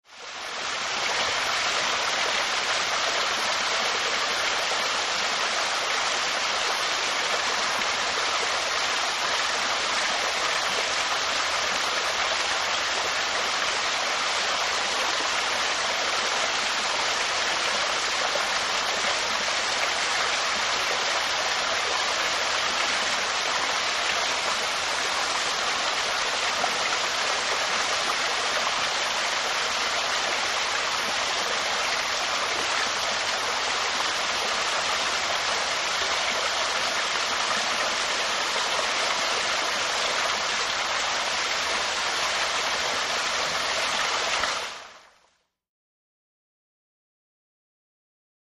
Water, Waterfall | Sneak On The Lot